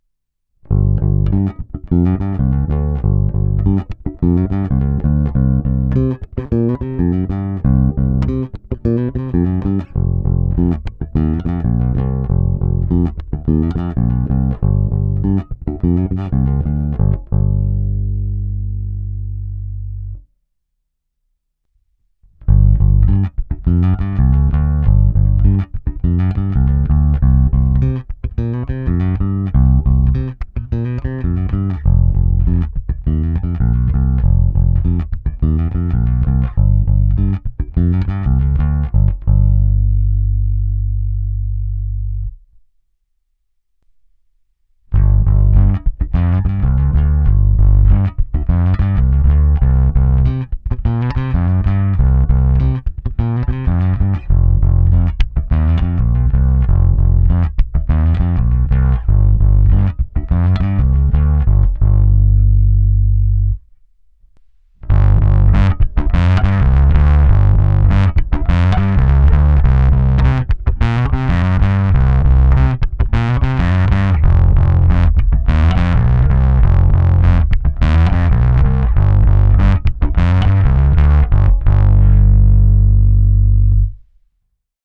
Když se zaměřím na čistý zvuk, tak ten je křišťálově čistý, pevný, konkrétní, absolutně bez šumu.
Následuje nahrávka na pětistrunnou baskytaru Fender American Professional II Precision Bass V s niklovými roundwound strunami Sadowsky Blue Label v dobrém stavu. Nejprve je nahrávka baskytary rovnou do zvukové karty, pak čistý kanál, následuje zkreslení na čistém kanálu vytočením jeho gainu za půlku, a nakonec zkreslený kanál s gainem na dosti nízkou hodnotu, prostě zvuk, který se mi ještě líbil, brutálnější zkreslení jsem nenahrával. První ukázka je taková klasika, druhou jsem udělal zejména kvůli ukázce zvuku na struně H.